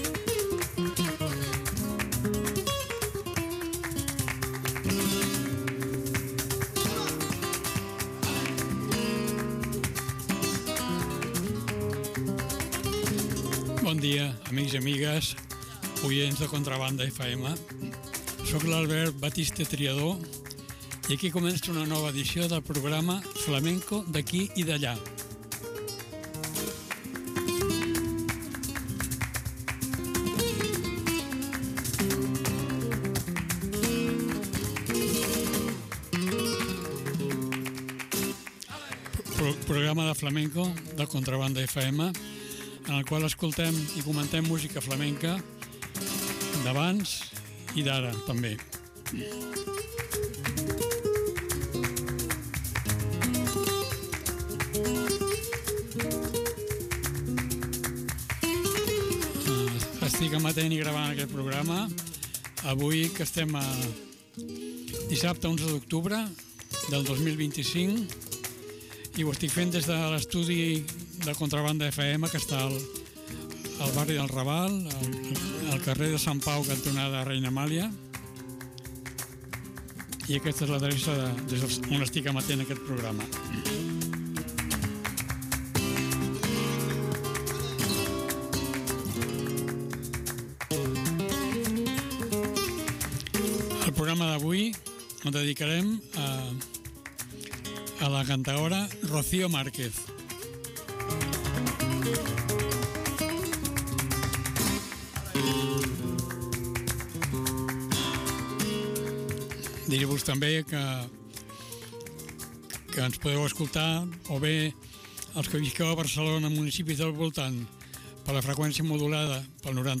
Amb motiu del concert que va fer Rocío Márquez a les festes de la Mercè, repassem de nou la trajectòria d’aquesta cantaora.
Fandangos de Alosno y naturales